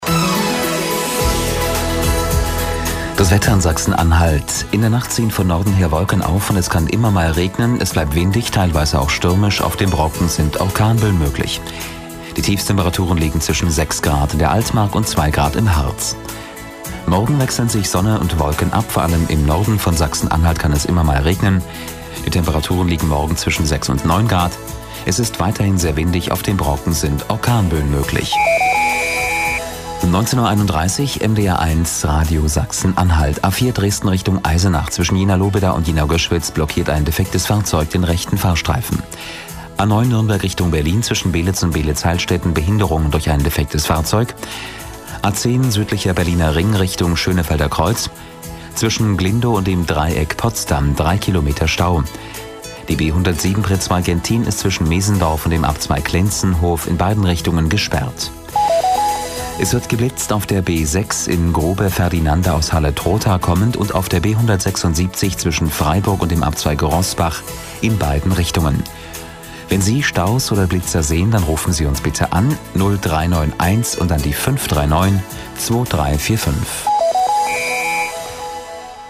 warm, freundlich, angenehm, verbindlich, symphatisch, jugendlich bis beste Jahre, wandelbar, seriös,
Kein Dialekt
Sprechprobe: eLearning (Muttersprache):